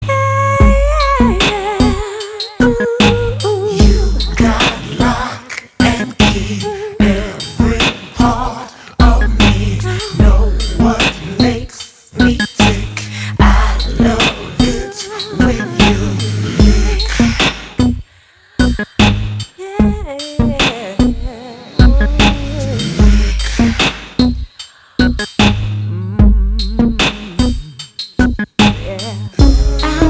• Качество: 250, Stereo
ритмичные
женский вокал
dance
Electronic
спокойные
club
медленные
vocal